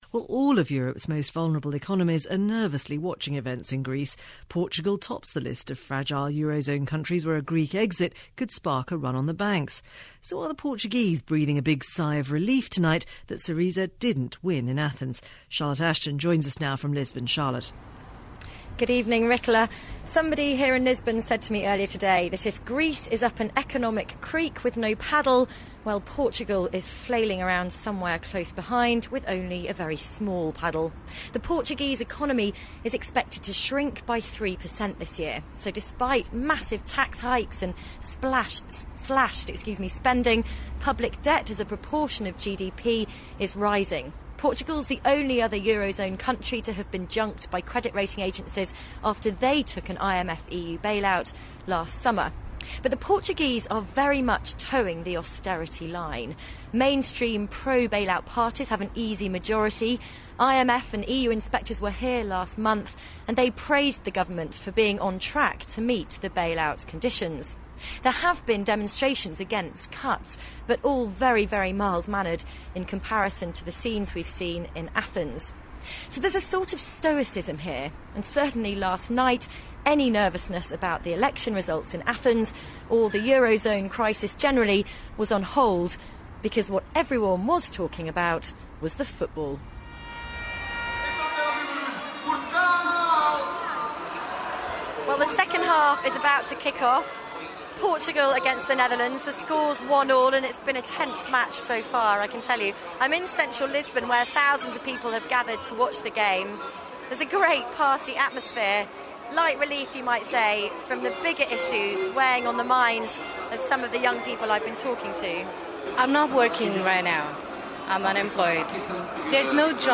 I'm in Lisbon to ask whether the Portuguese government will also demand softer bailout conditions. Because there's no doubt this country is hurting under the austerity agreement.